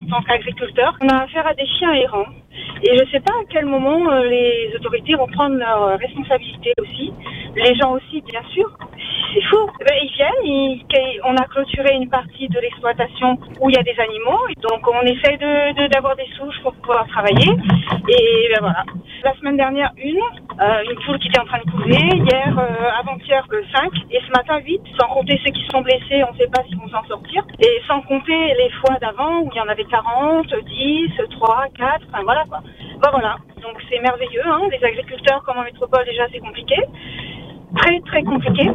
Le témoignage de la femme d’un agriculteur illustre cette réalité quotidienne devenue insupportable.